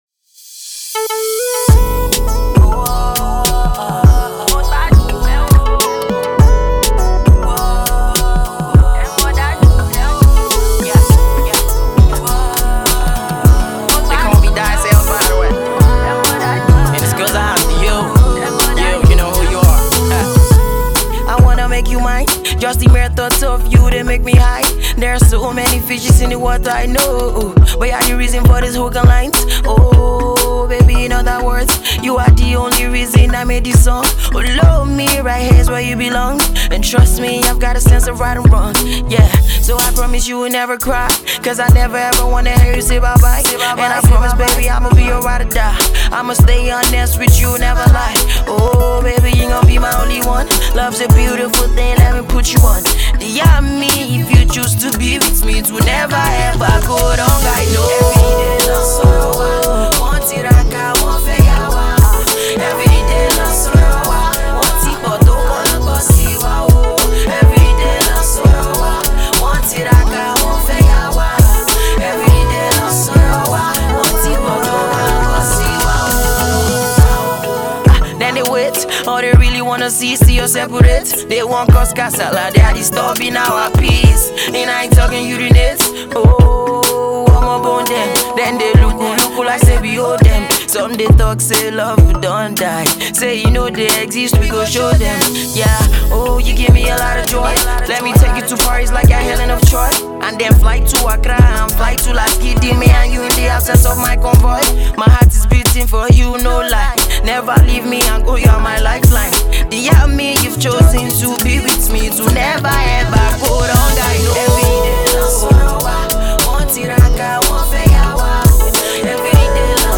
who is a rapper and singer.
contemporary Afro-pop